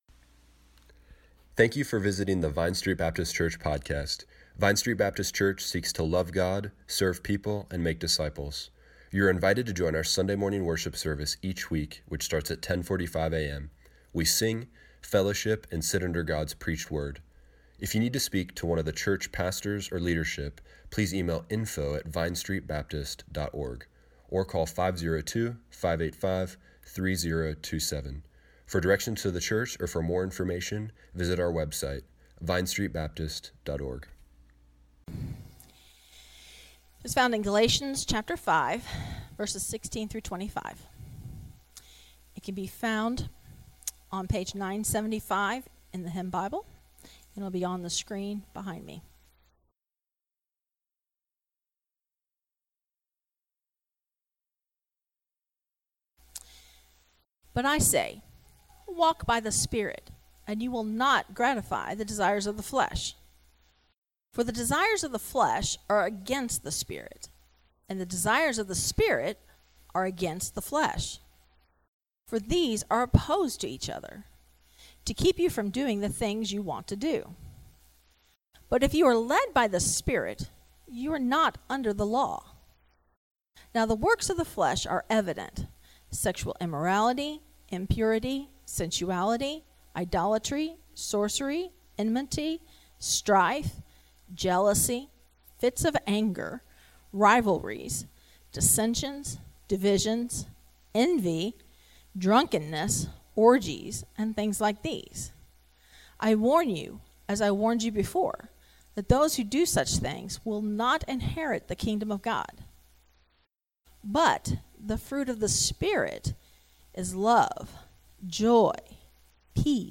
Morning Worship
2020 How to we fight sin? Walk by the spirit You cannot run in two directions at the same time The flesh has already been defeated Click here to listen to the sermon online.